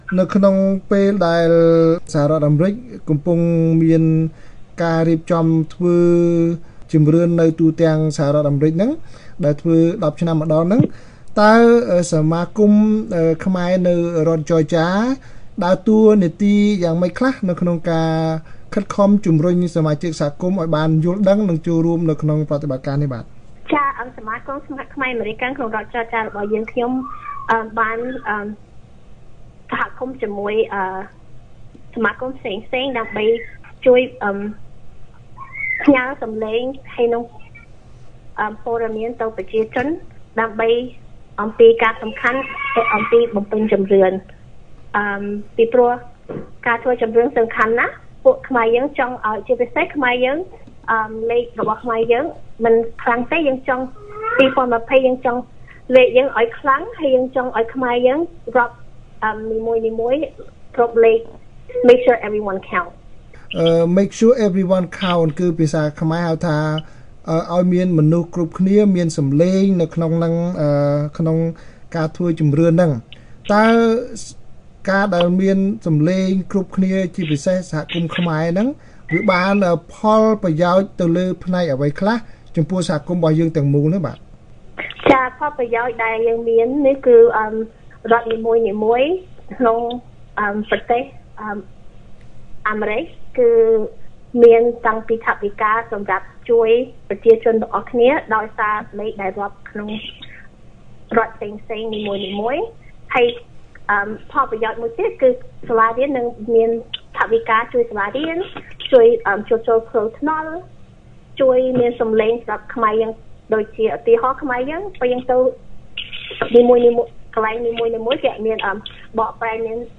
បទសម្ភាស VOA៖ សកម្មជនធ្វើយុទ្ធនាការជំរុញឱ្យខ្មែរអាមេរិកាំងបំពេញជំរឿនឆ្នាំ២០២០